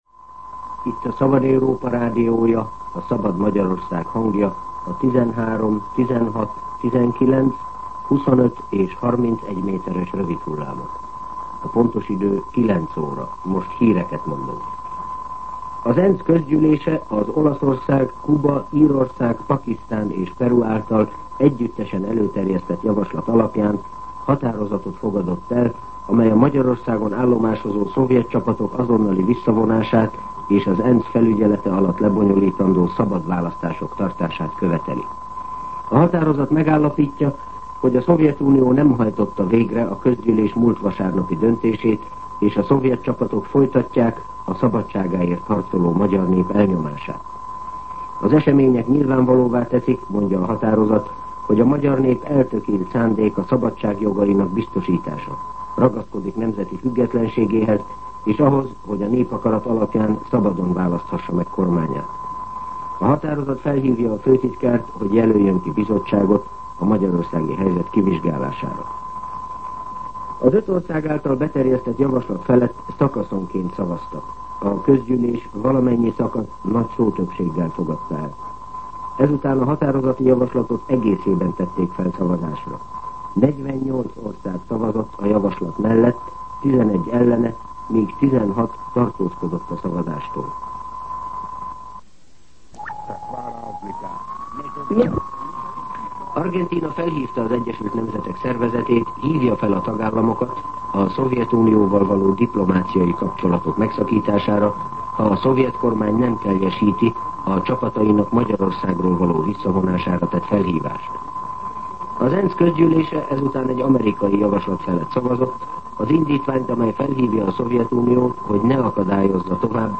09:00 óra. Hírszolgálat